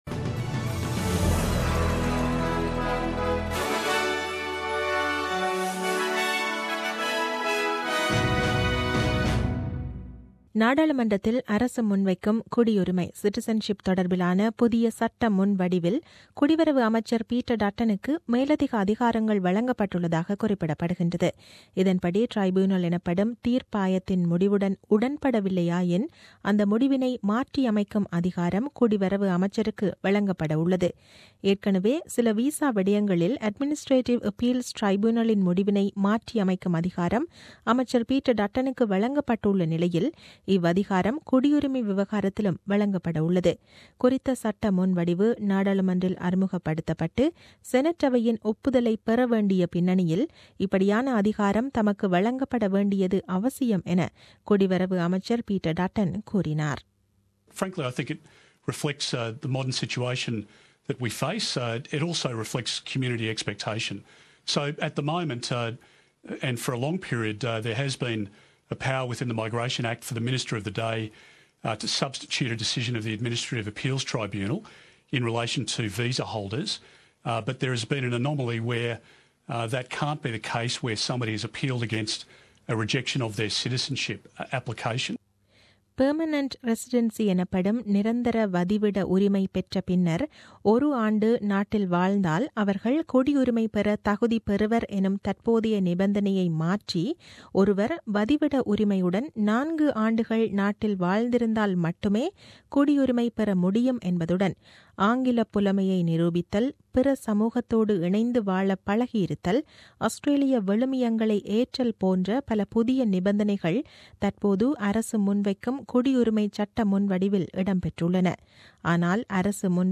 The news bulletin aired on 12 June 2017 at 8pm.